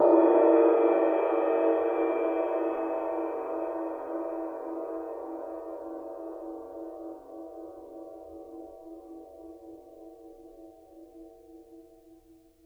susCymb1-hit_pp_rr2.wav